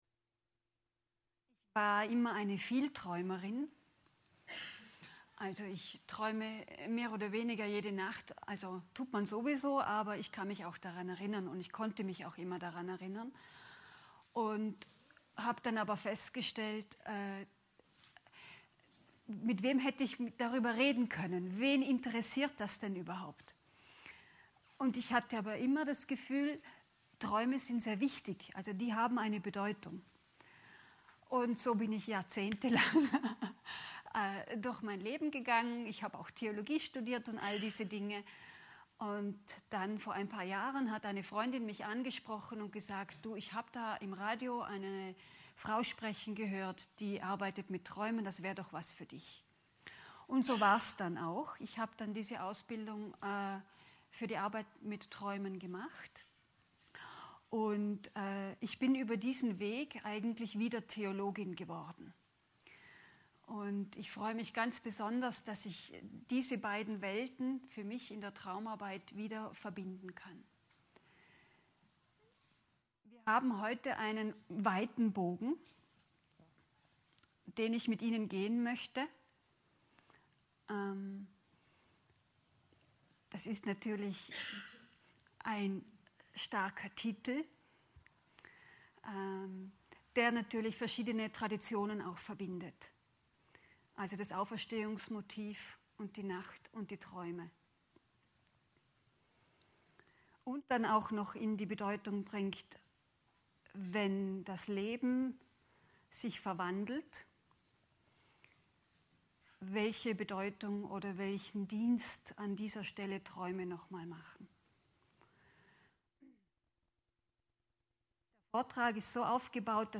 Mitschnitt des Vortrags vom 23. September 2016